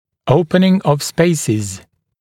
[‘əupənɪŋ əv ‘speɪsɪz][‘оупэн ов ‘спэйсиз]открытие промежутков